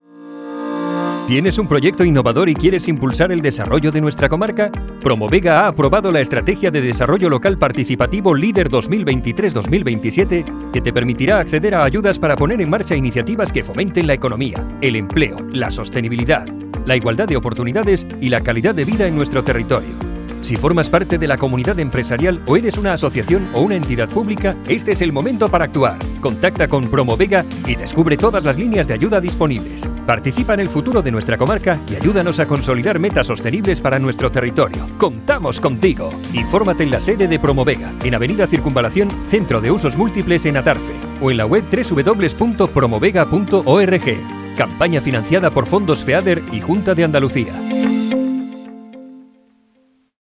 Numerosas emisoras de radio locales, se han hecho eco de la noticia, que puede escucharse aquí.
cuna-radio-PROMOVEGA.mp3